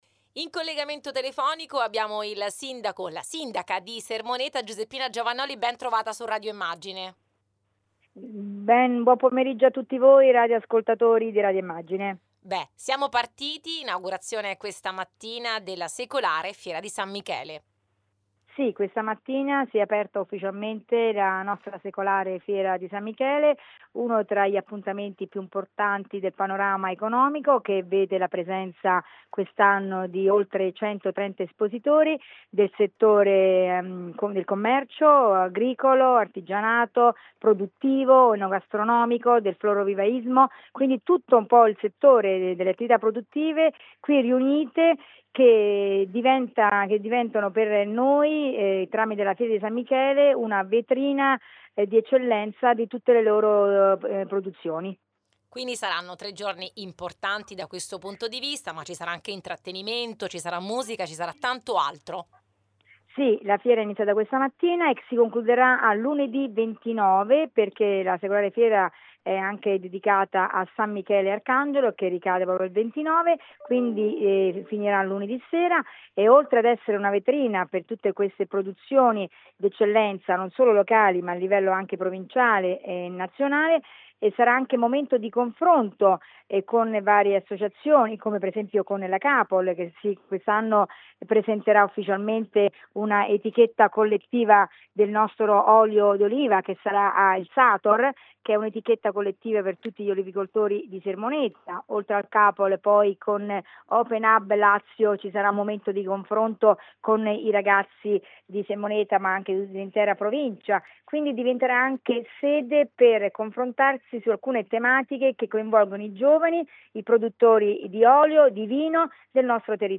La sindaca Giovannoli  a Gr Latina